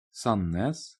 Category:Norwegian pronunciation of cities